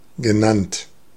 Ääntäminen
IPA : /ˈkɔːld/ IPA : /ˈkɔld/